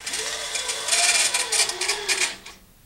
Slide Curtain Open, Rattles